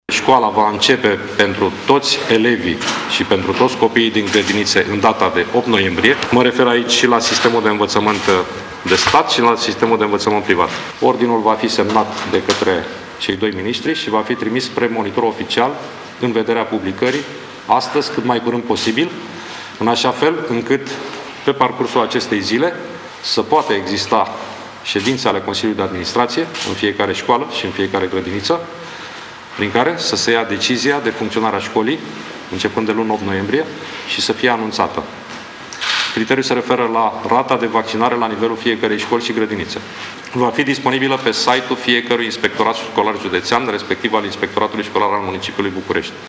Consiliile de Administrație ale școlilor decid cum se va face școala începând de luni a spus azi într-o conferință de presă ministrul Educației. Dacă rata de vaccinare a personalului din școală sau grădiniță depășește 60 %, atunci școala se va face cu prezență fizică.